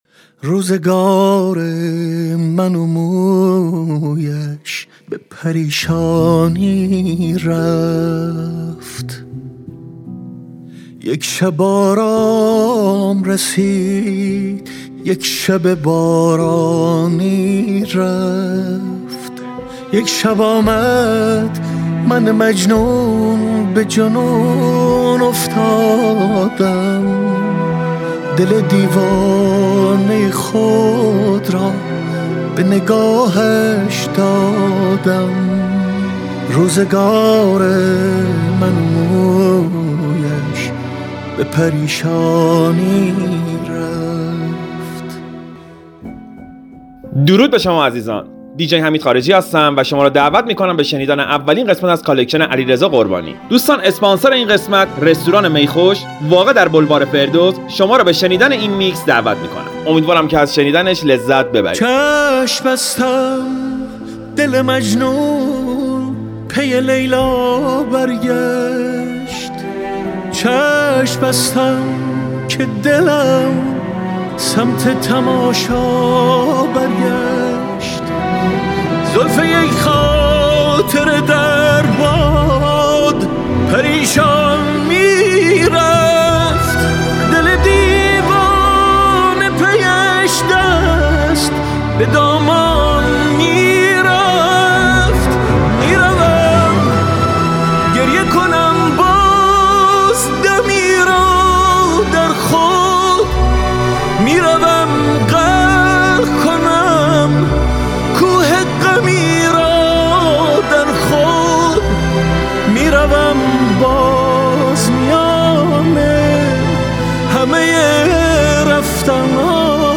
موسیقی سنتی